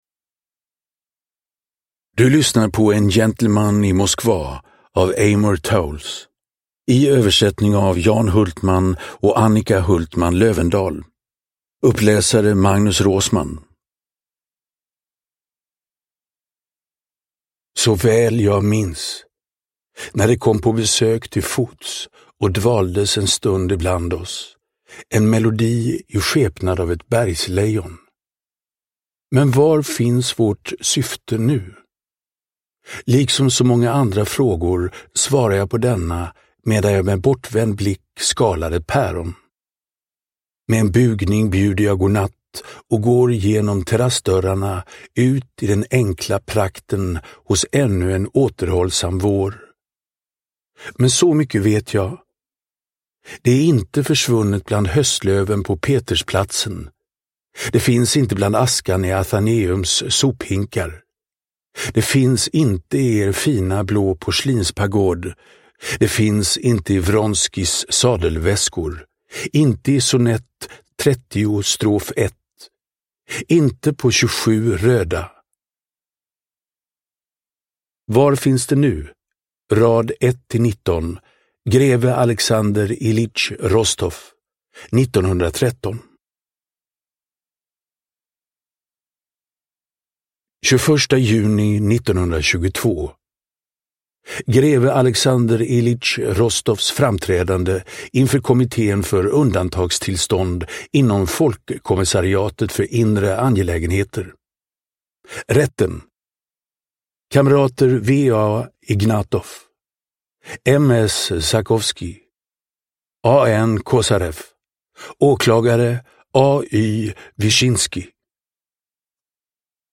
En gentleman i Moskva – Ljudbok – Laddas ner
Uppläsare: Magnus Roosmann